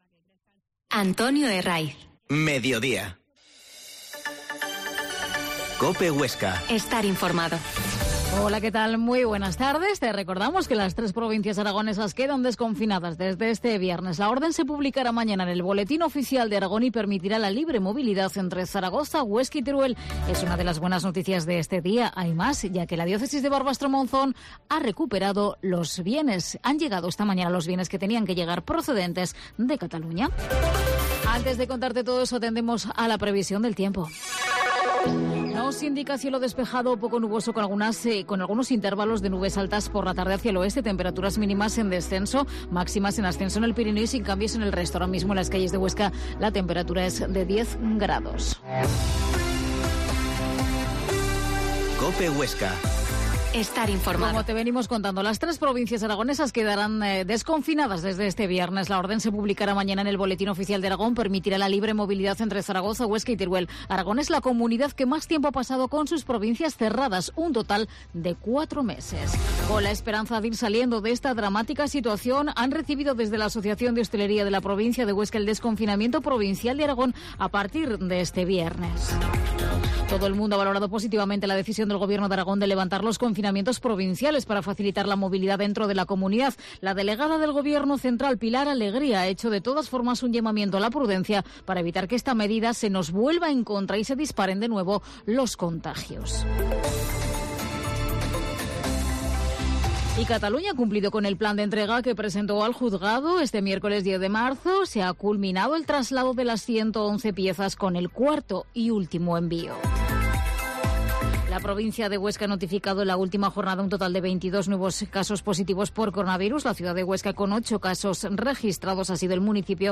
Mediodia en COPE Huesca 13.20 Entrevista al concejal de servicios generales y medio ambiente Roberto Cacho